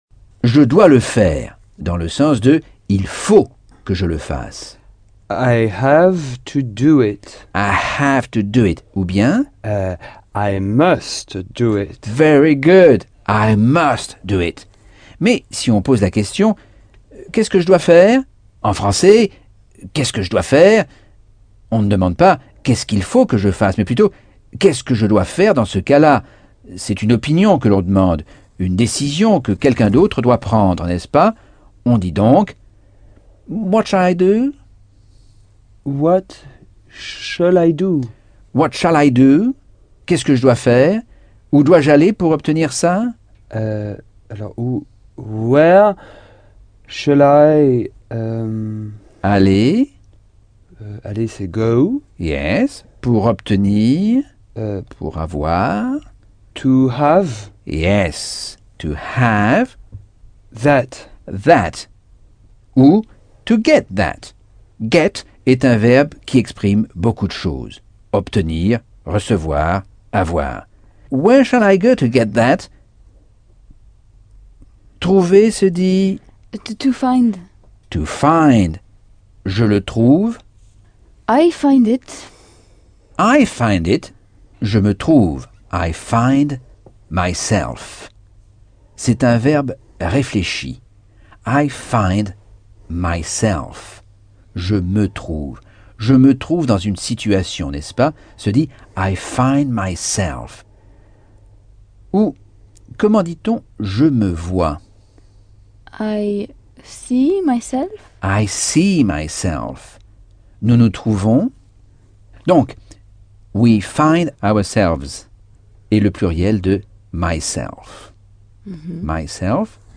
Leçon 2 - Cours audio Anglais par Michel Thomas - Chapitre 11